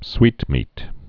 (swētmēt)